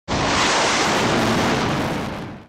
craft launch 01